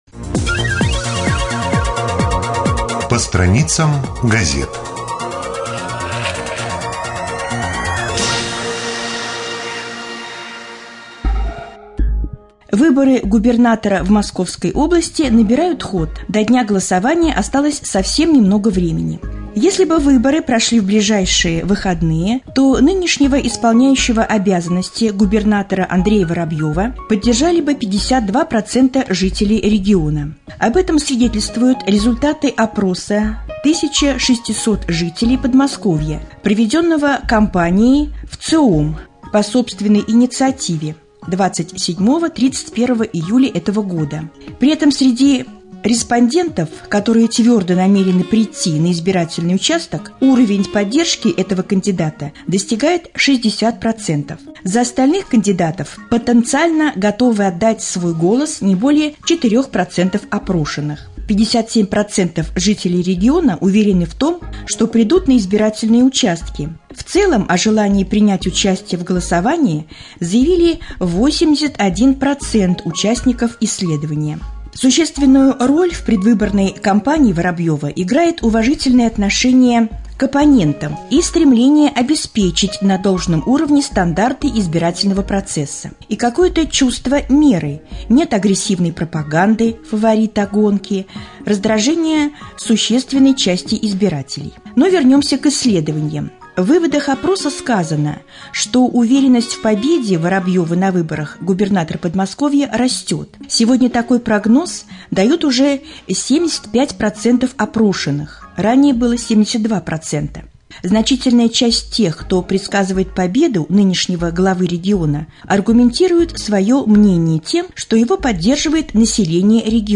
в эфире раменского радио 13.08.2013г. - РамМедиа - Раменский муниципальный округ - Раменское